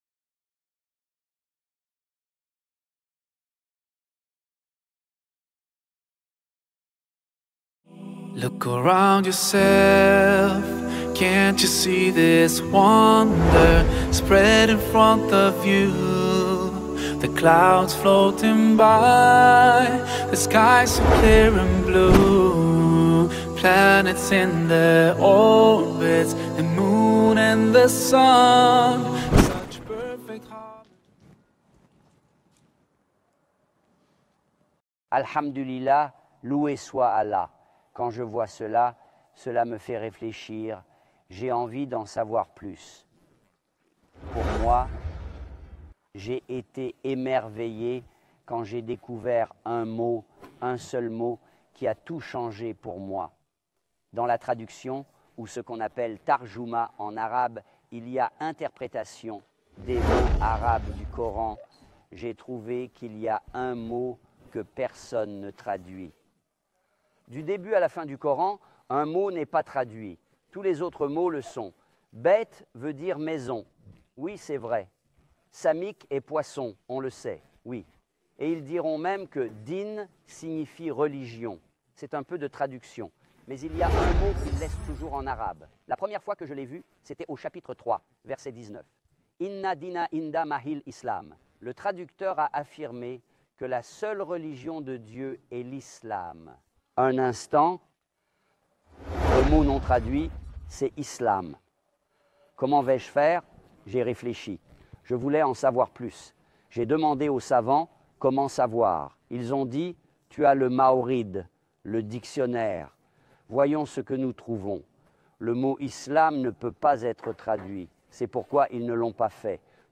filmée dans le décor de paysages pittoresques et de lieux historiques de Jordanie. Dans cet épisode, il explique les piliers de la foi — la croyance en Dieu, Ses anges, Ses prophètes, Ses Livres, au Jour Dernier et le décret divin.